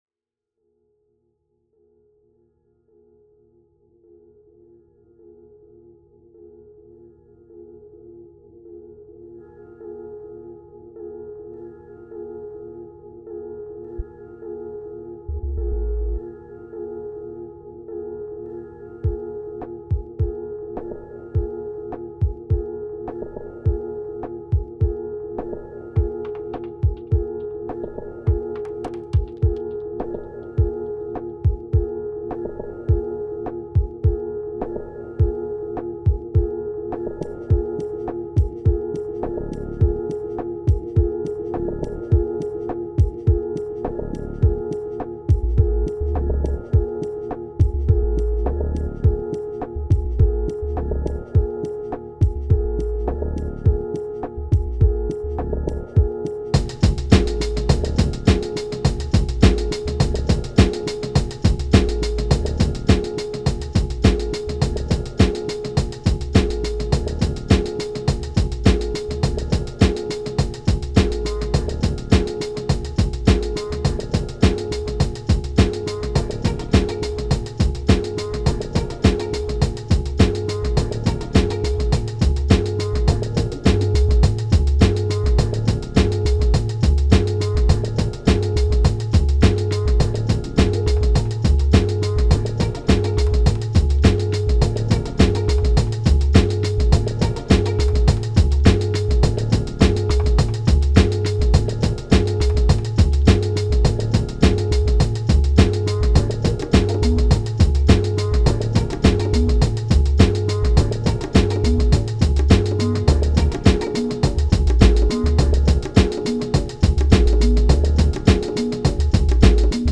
Energy Wakeup Emerge Riddim Hypnotic Varia Percusions Noise